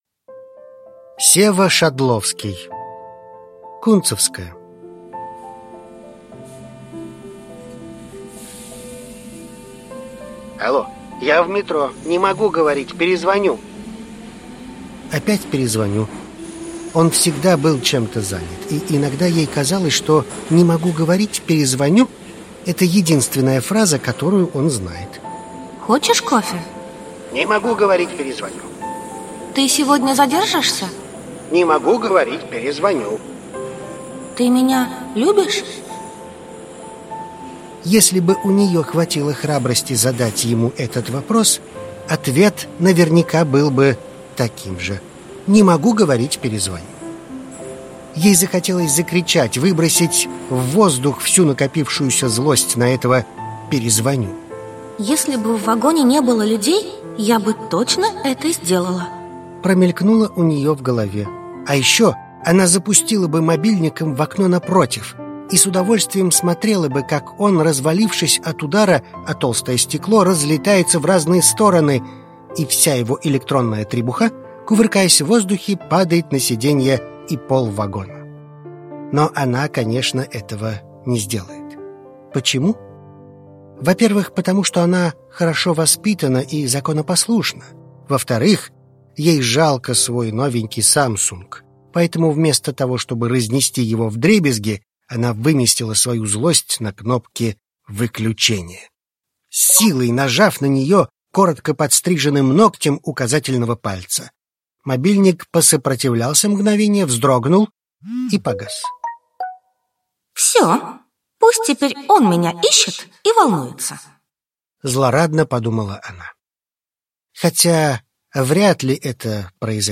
Аудиокнига Кунцевская | Библиотека аудиокниг
Прослушать и бесплатно скачать фрагмент аудиокниги